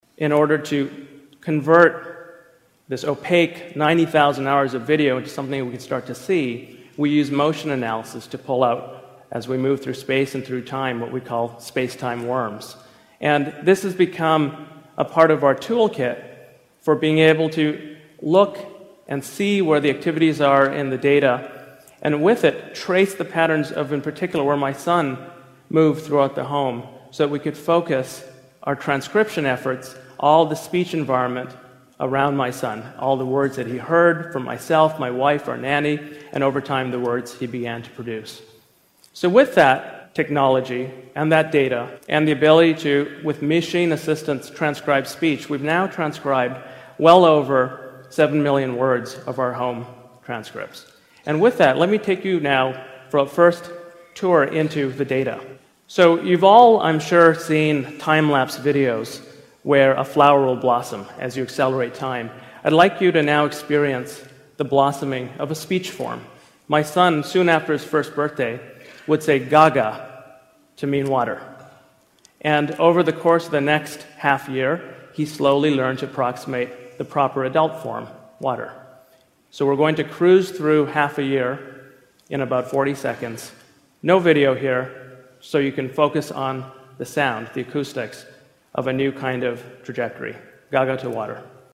TED演讲：单词的诞生(3) 听力文件下载—在线英语听力室